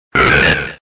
Cri de Tarinor dans Pokémon Diamant et Perle.